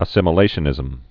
(ə-sĭmə-lāshə-nĭzəm)